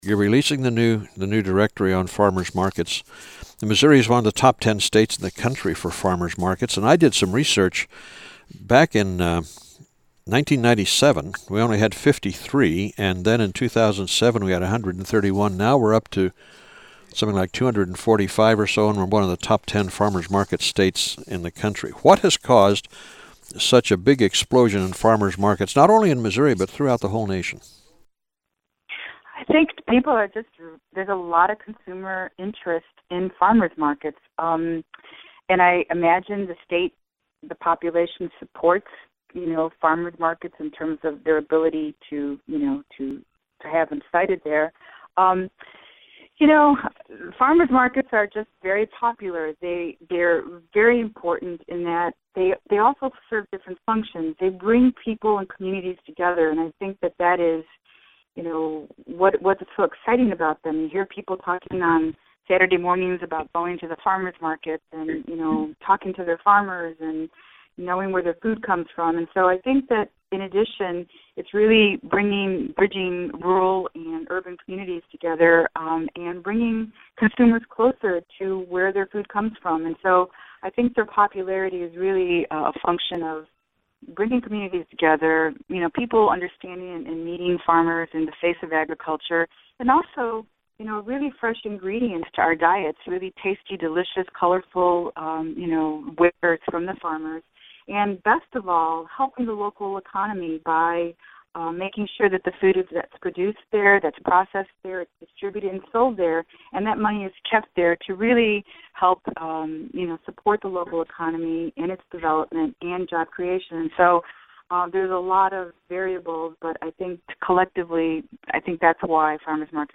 AUDIO: Alonzo interview 10:27 Share this: Facebook Twitter LinkedIn WhatsApp Email